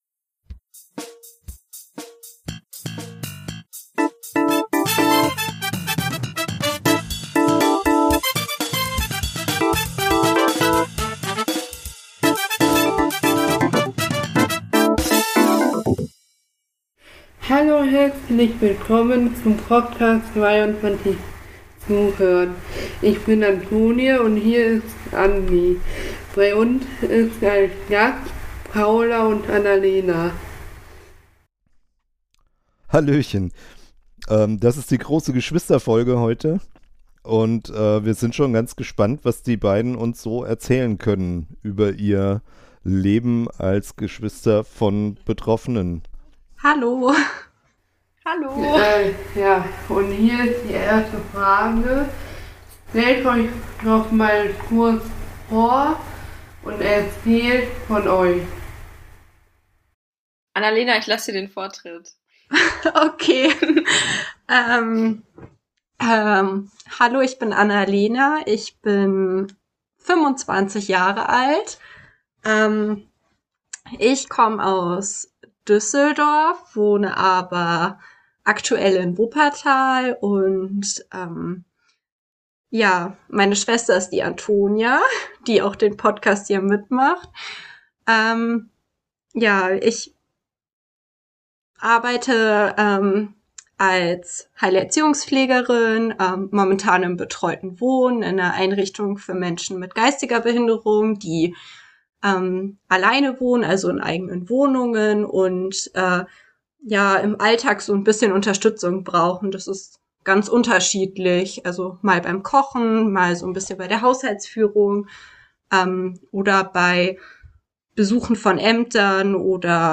Gespräch mit zwei Schwestern